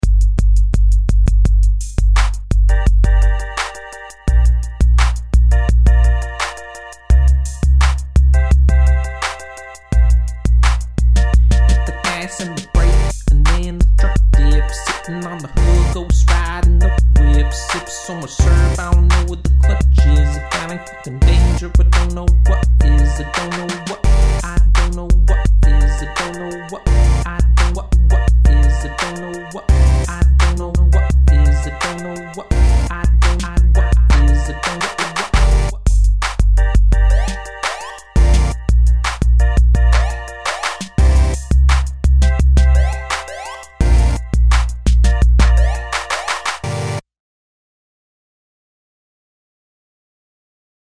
It is not complete, as you will notice. This is because I wanted some rap verses on it, so I figured I'd put it out there as is (basically a beat and a hook) and see if anyone is interested.
As with my space rap song, there is some boomy bass that will likely not come through on laptop speakers.
Bwooop, bwooop